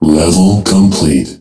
Level_Complete.wav